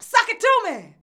SUCK IT.wav